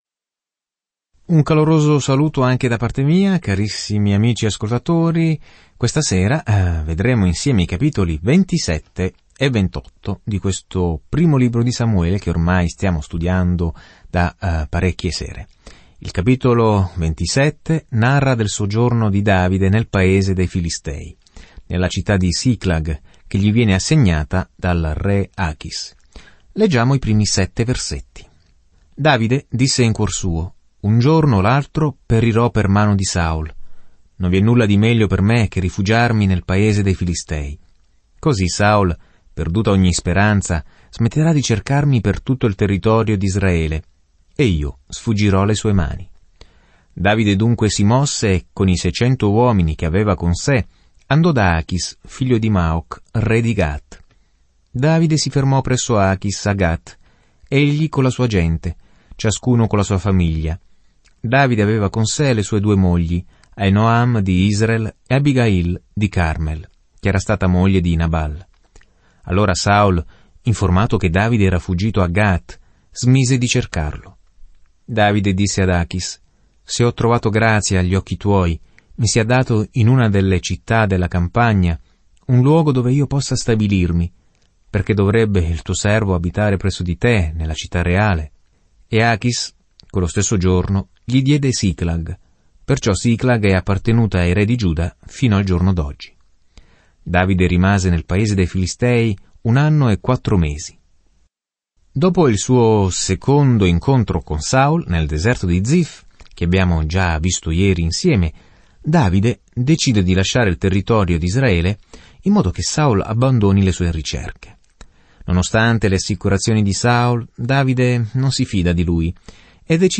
Scrittura Primo libro di Samuele 27 Primo libro di Samuele 28 Giorno 13 Inizia questo Piano Giorno 15 Riguardo questo Piano Il primo Samuele inizia con Dio come re di Israele e continua la storia di come Saul, poi Davide, divenne re. Viaggia ogni giorno attraverso Primo Samuele mentre ascolti lo studio audio e leggi versetti selezionati della parola di Dio.